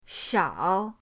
shao3.mp3